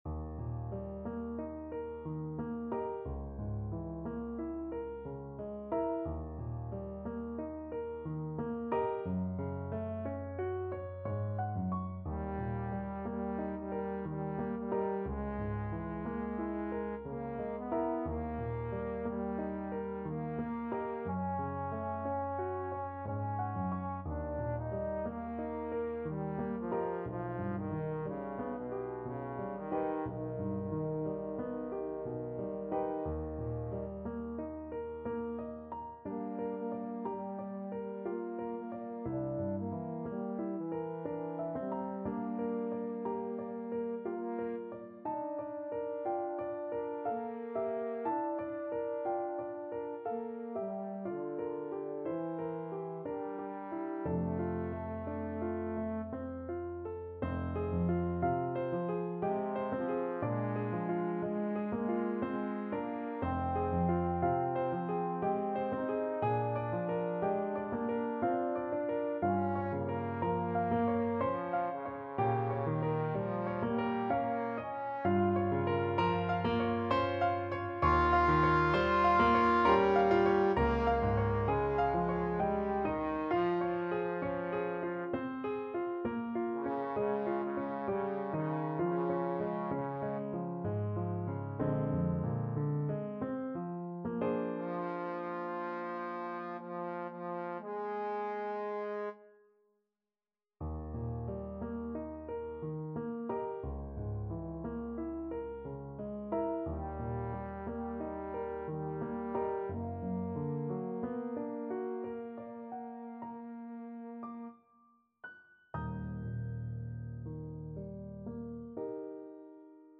Trombone version
3/4 (View more 3/4 Music)
Andante ma non troppo =60
Classical (View more Classical Trombone Music)